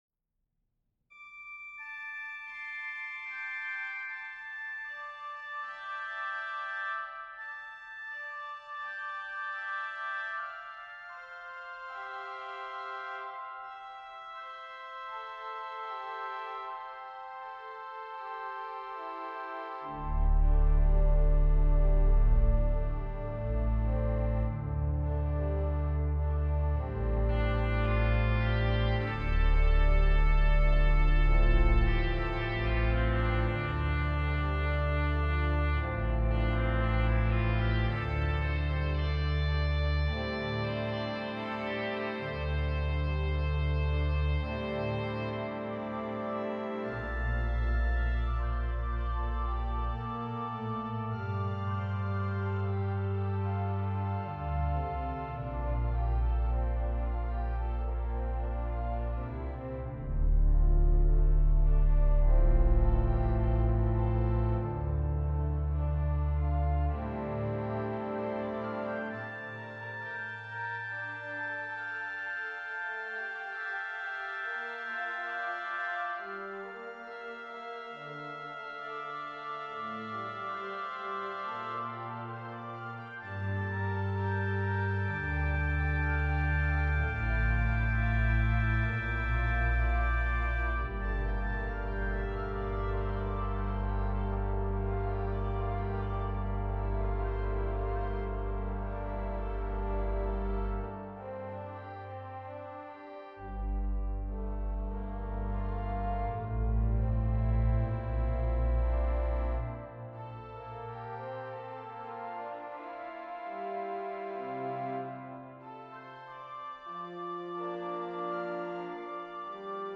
for Organ (2025)
The final F/E falling semi-tone lingers.